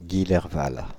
Guillerval (French pronunciation: [ɡilɛʁval]